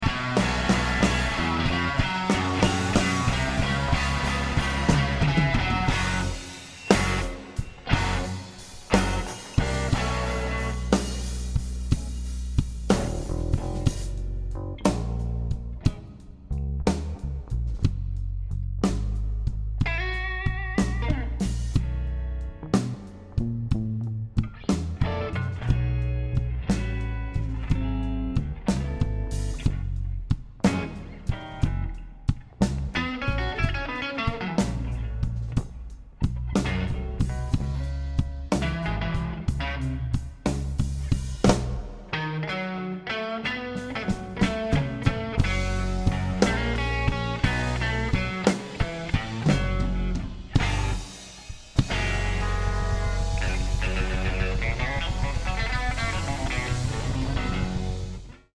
Slow Blues On A Hamiltone Sound Effects Free Download